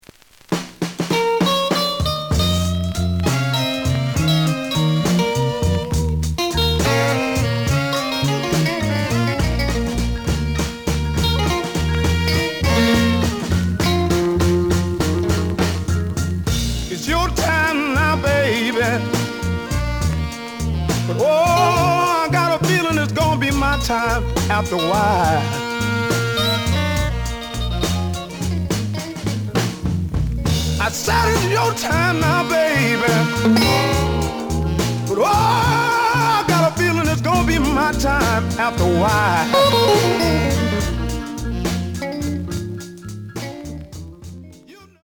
●Format: 7 inch
●Genre: Blues